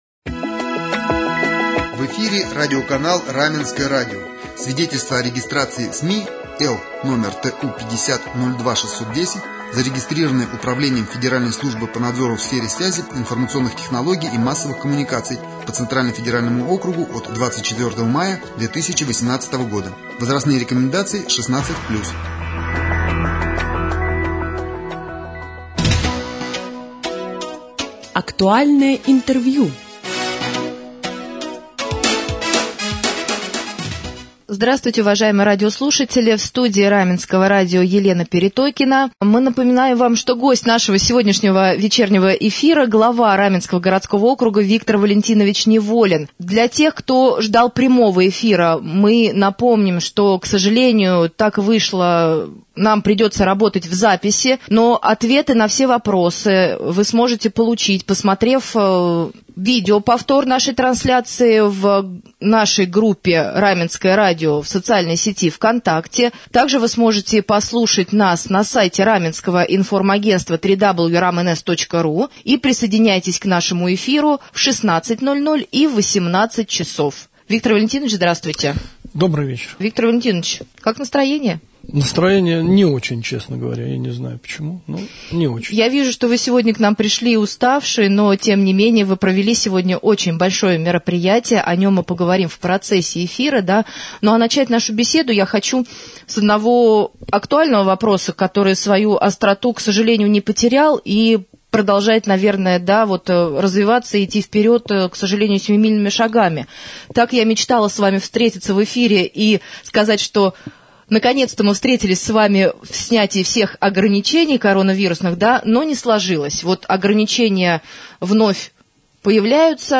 В вечернем эфире на Раменском радио глава Раменского городского округа Виктор Неволин ответил на вопросы слушателей.